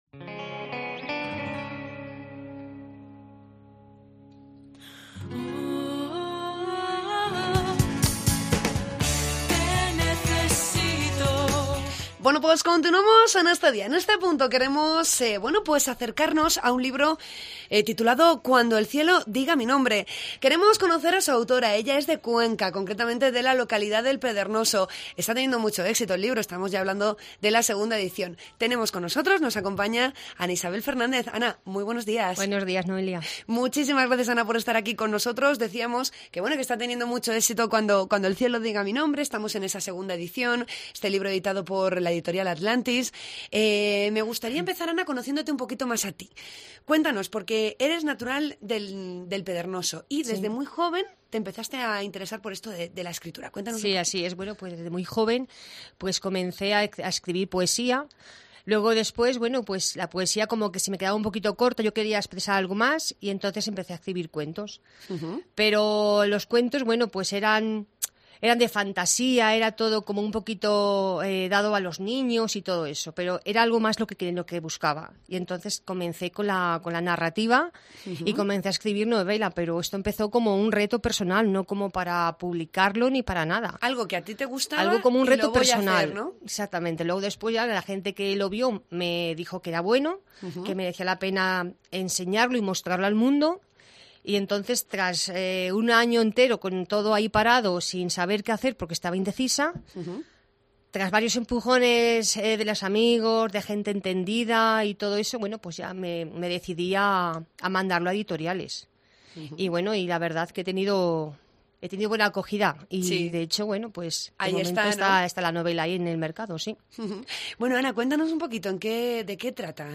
Entrevistamos a la escritora conquense